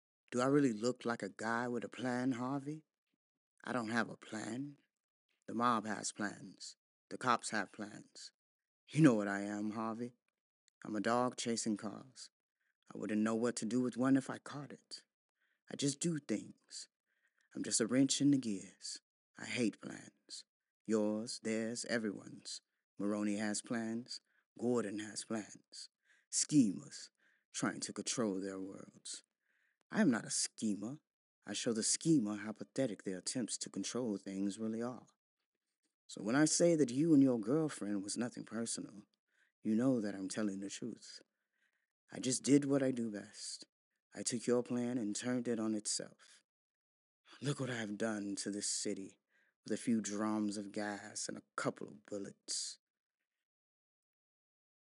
Demos
Diverse Commercial styles Male and Female